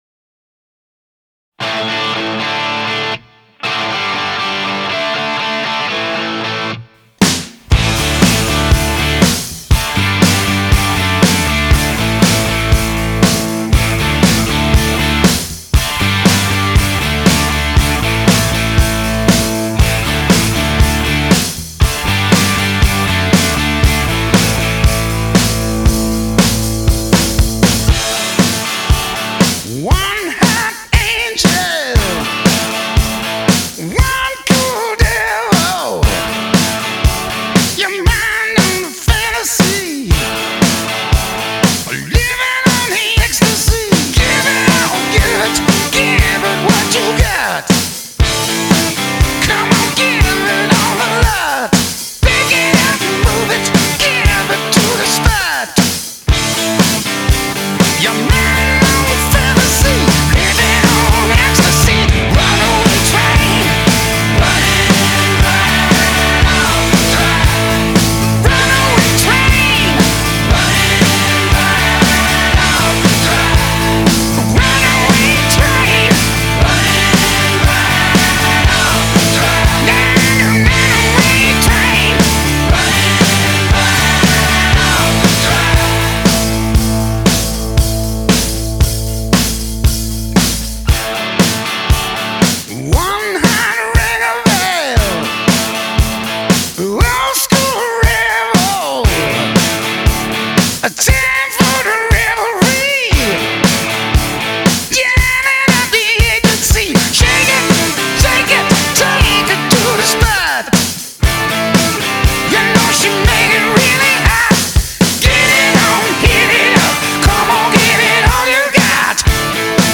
Hard Rock, Classic Rock